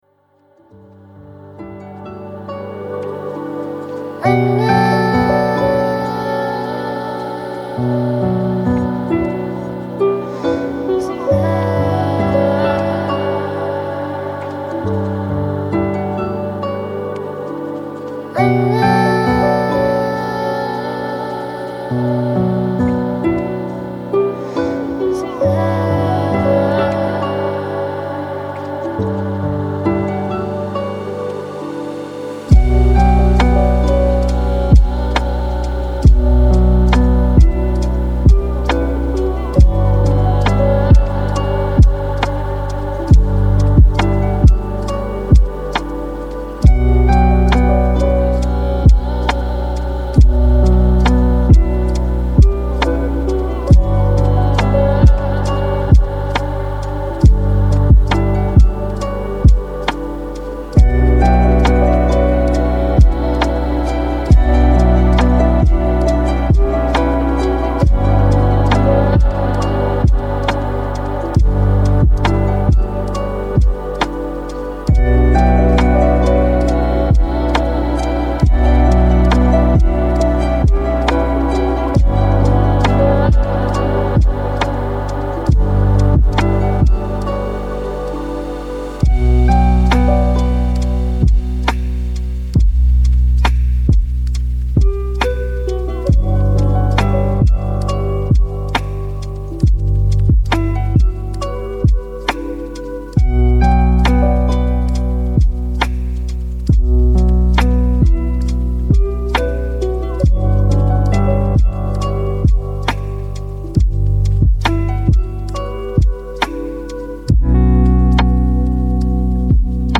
Vagues Naturelles : Focus 25 min